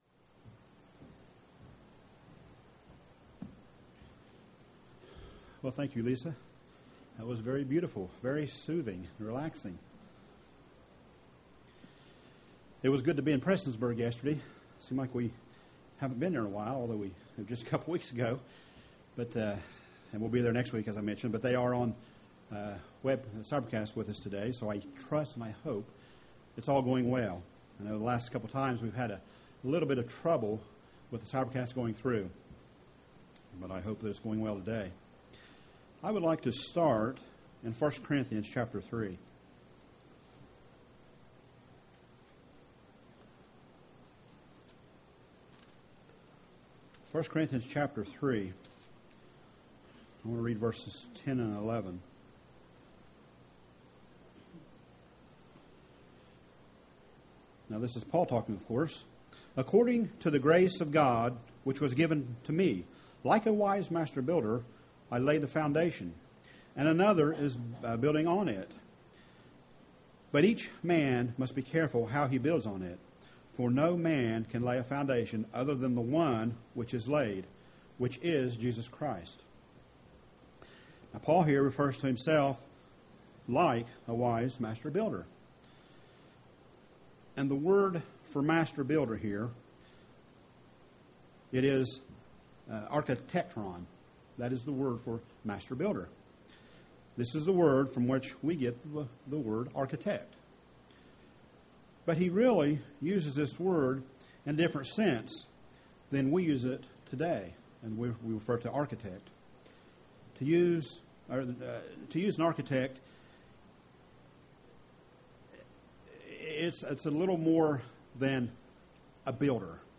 Sermons
Given in Portsmouth, OH